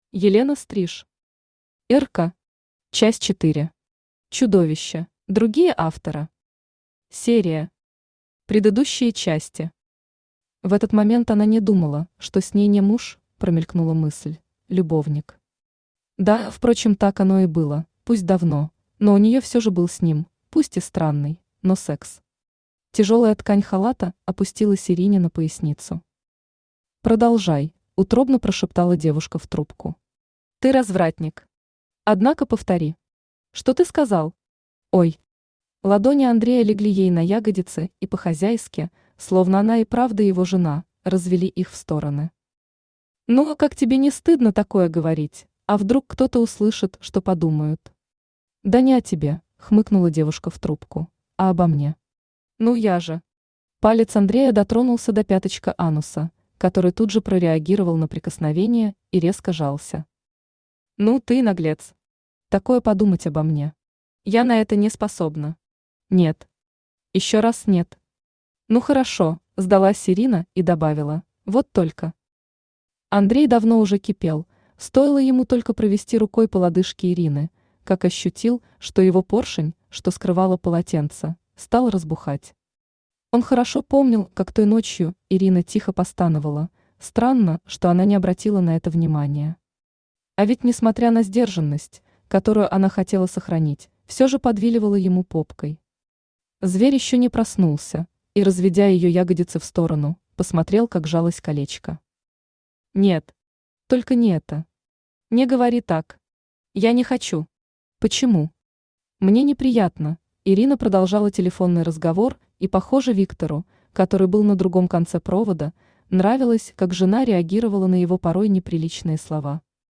Аудиокнига Ирка. Часть 4. Чудовище | Библиотека аудиокниг
Чудовище Автор Елена Стриж Читает аудиокнигу Авточтец ЛитРес.